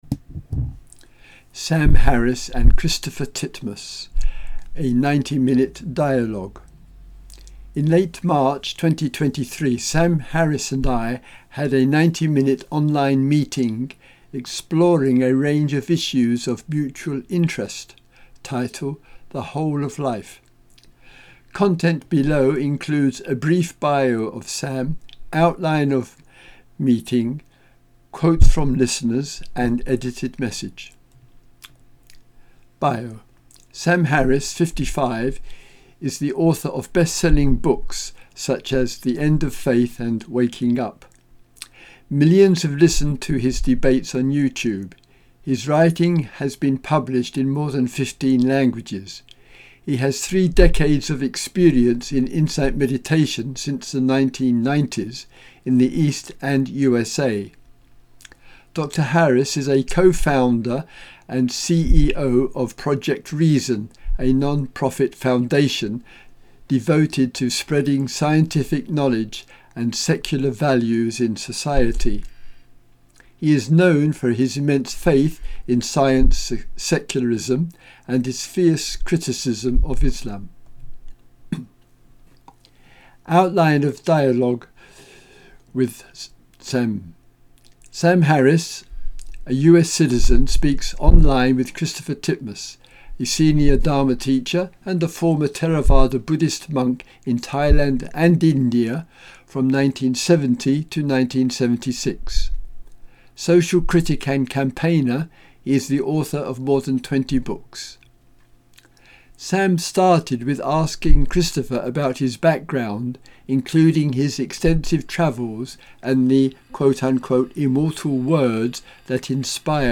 Audio recording of this blog